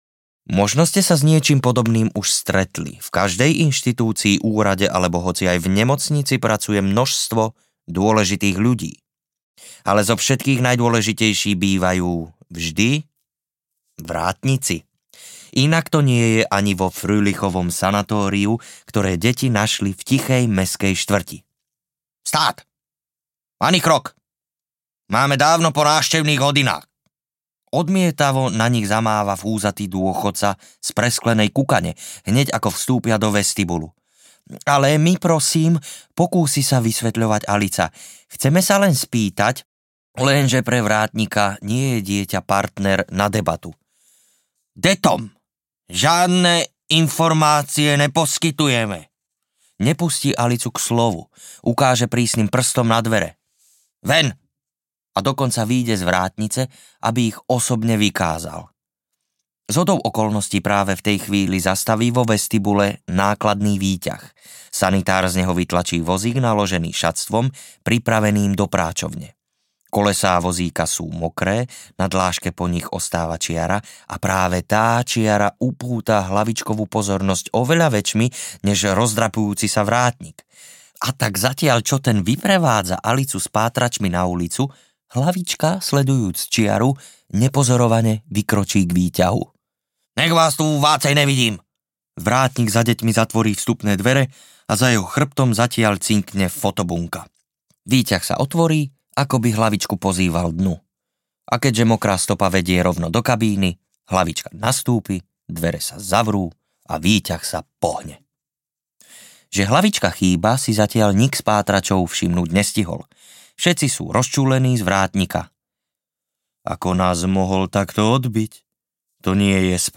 Náš brat Hlavička audiokniha
Ukázka z knihy